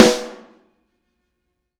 R_B Snare 01 - Close.wav